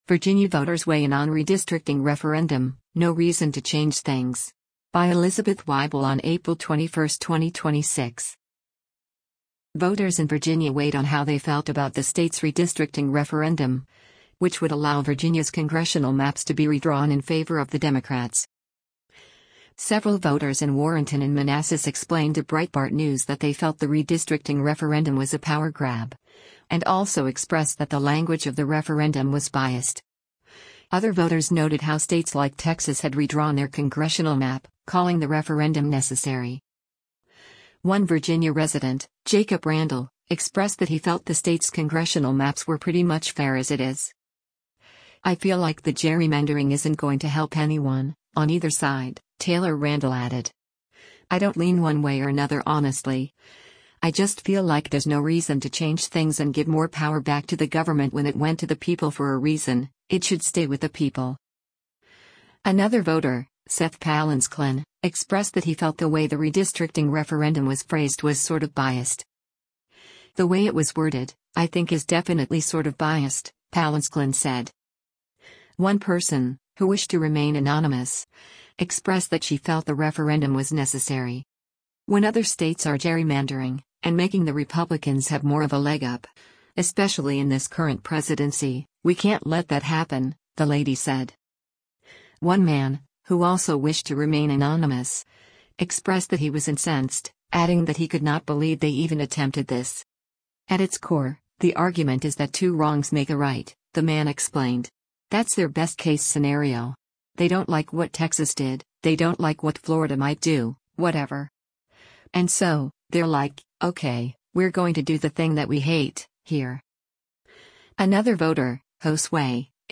Several voters in Warrenton and Manassas explained to Breitbart News that they felt the redistricting referendum was a “power grab,” and also expressed that the language of the referendum was “biased.”